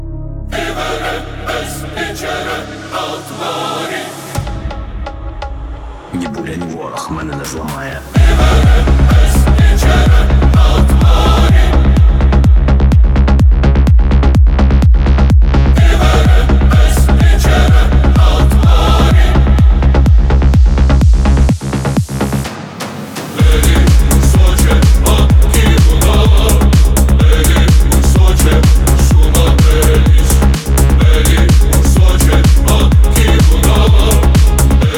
Techno Dance
Жанр: Танцевальные / Техно / Украинские